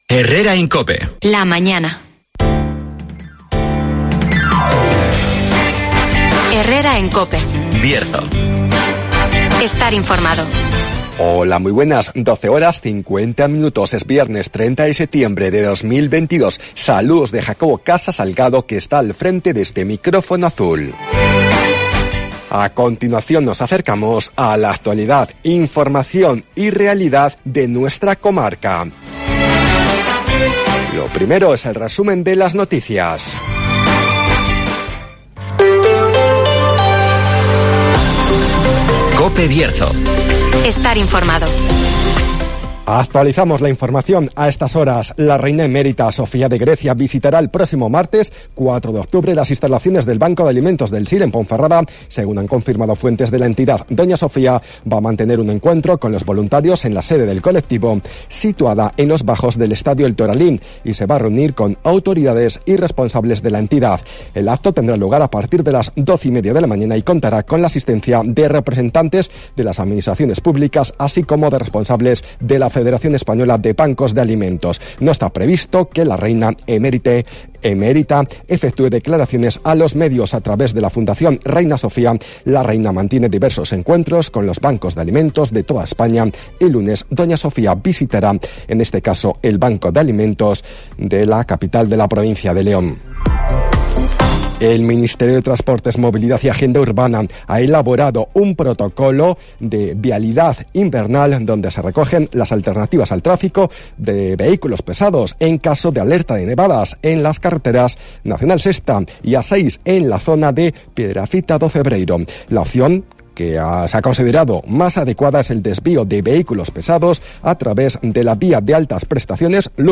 Resumen de las noticias, el tiempo y la agenda